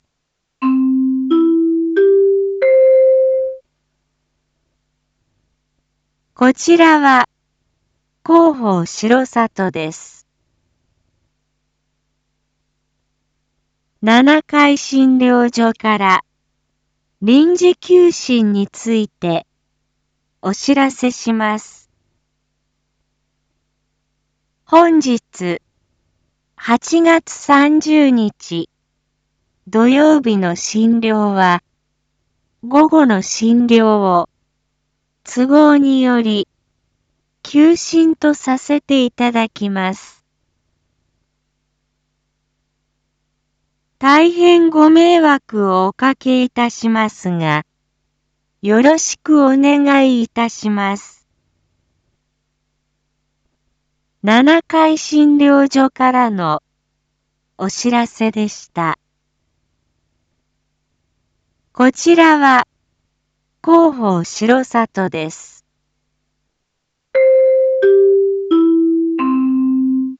Back Home 一般放送情報 音声放送 再生 一般放送情報 登録日時：2025-08-30 07:01:11 タイトル：R7.8.30七会診療所医科臨時休診② インフォメーション：こちらは広報しろさとです。